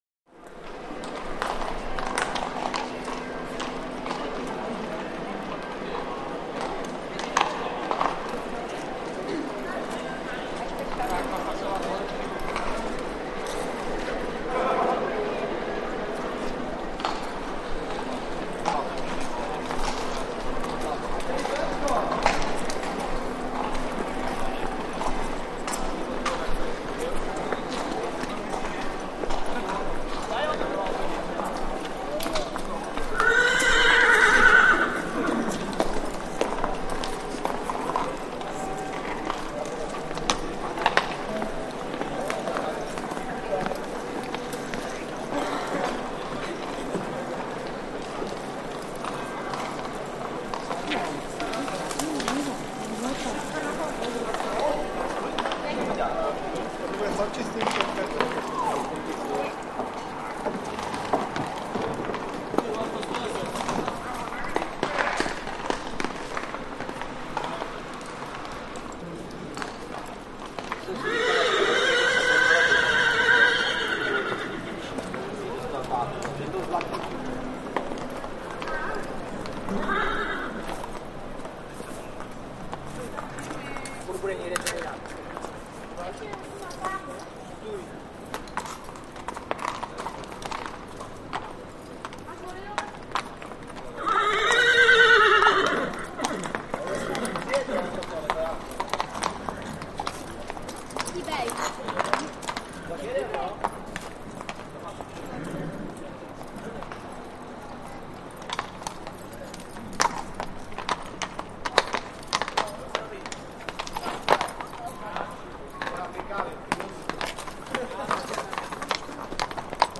Street BG
City Street 03, Light Activity, Wide Perspective City; Street With Distant Bell At Front, Light Walla, Horses Hooves On Cobbled Stone, Occaisional Whinnies And Shouts Etc.